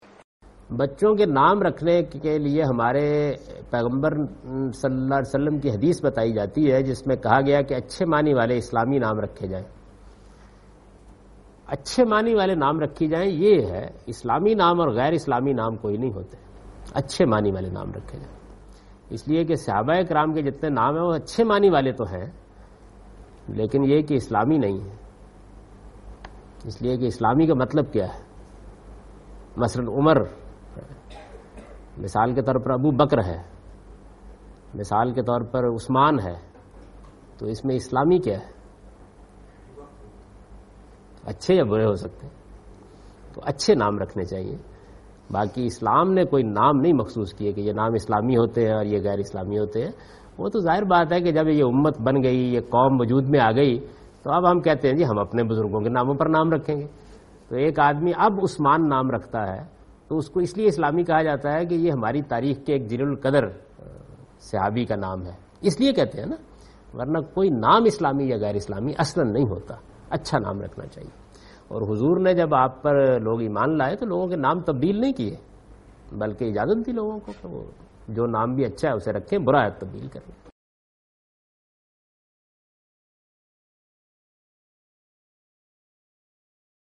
Javed Ahmad Ghamidi responds to the question 'Concept of Islamic or Unislamic names'?
جاوید احمد غامدی "اسلامی اور غیر اسلامی ناموں کا تصور " کے متعلق سوال کا جواب دے رہے ہیں